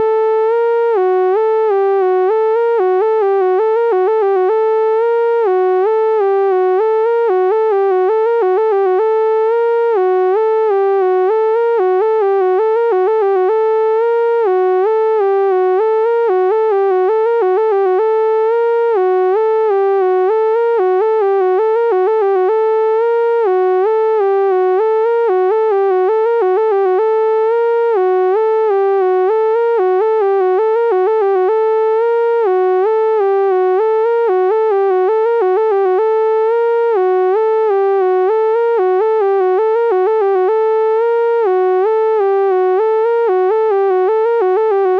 The melodic sequence below would have otherwise been playing linear eighth note rhythms, but was subjected to a exponential temporal skewing curve lasting 9 beats, causing an accelerando to happen. While the beats do get faster, the overall time is still 9 beats.